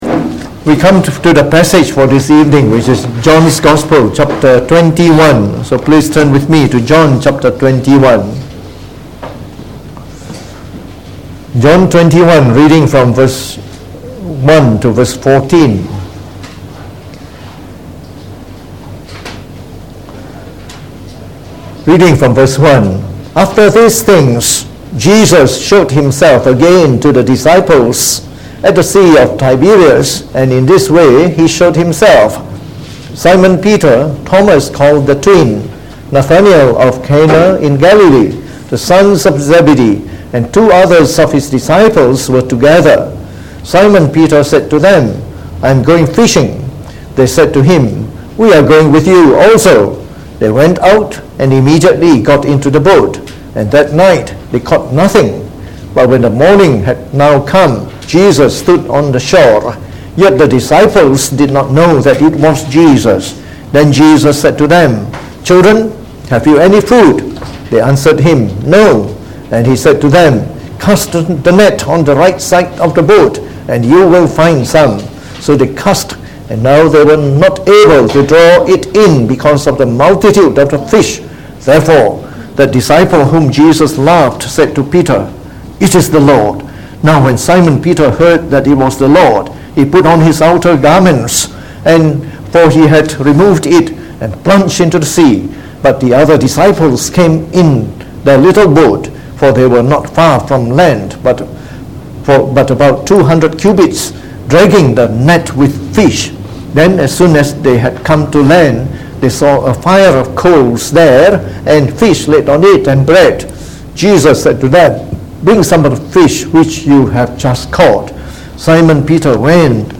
Preached on the 11th of Aug 2019. From our series on the Gospel of John delivered in the Evening Service